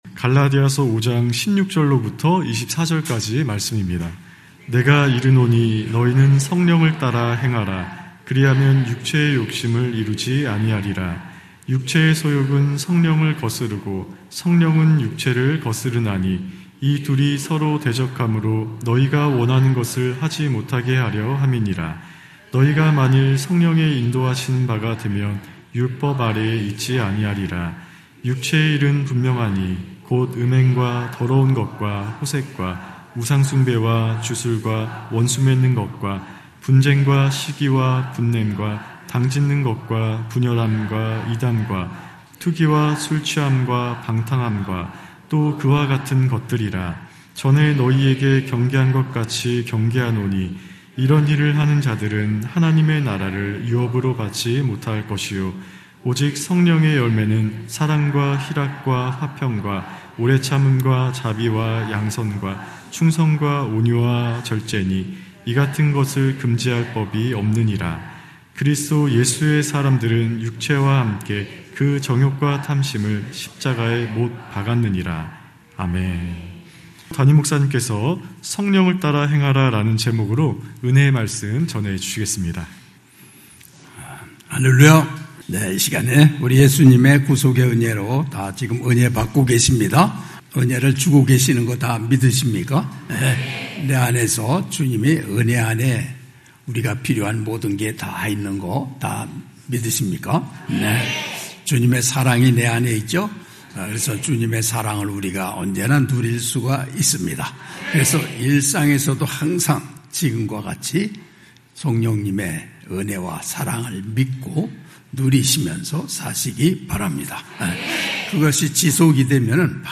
주일9시예배